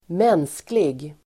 Uttal: [²m'en:sklig]